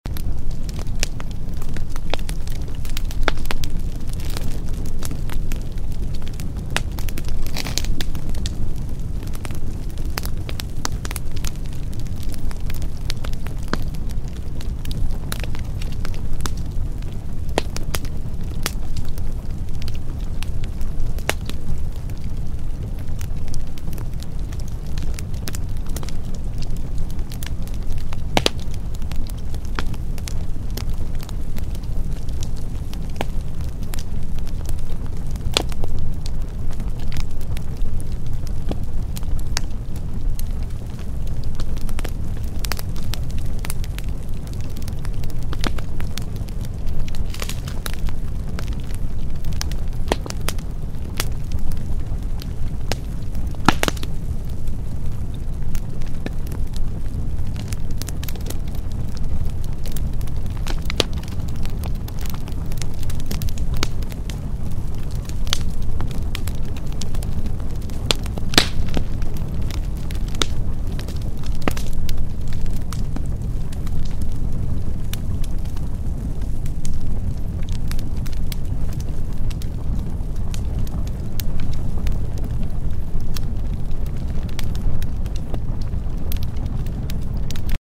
89052-FireCrackleSpeaker-SoundFile.mp3